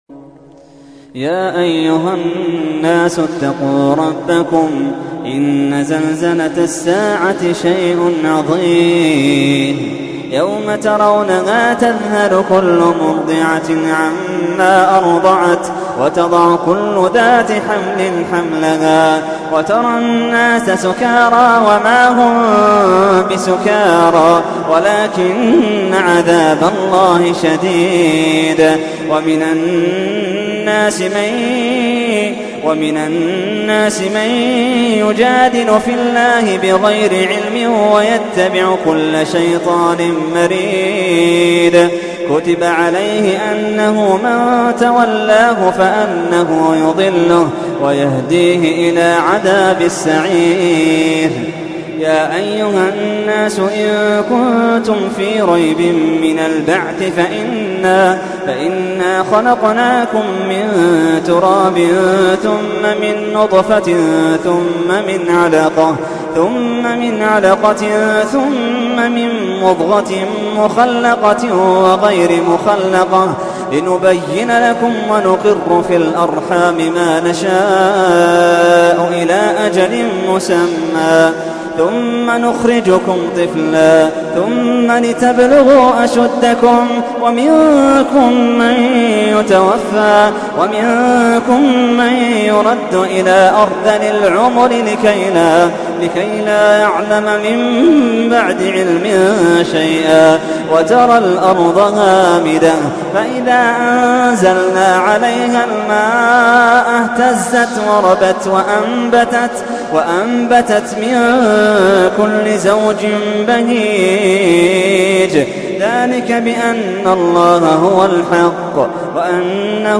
تحميل : 22. سورة الحج / القارئ محمد اللحيدان / القرآن الكريم / موقع يا حسين